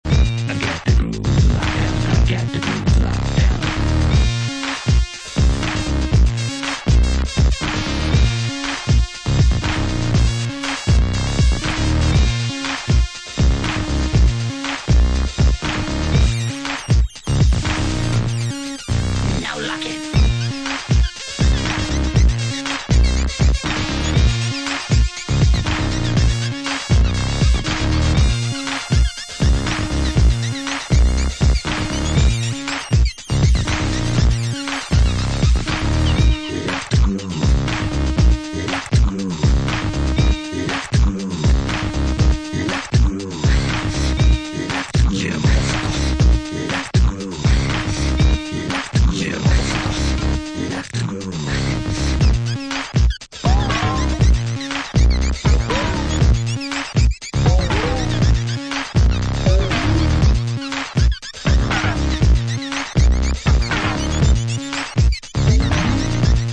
Fucked-up Finnish B-boys
electrobass and funk driven electronics
Electro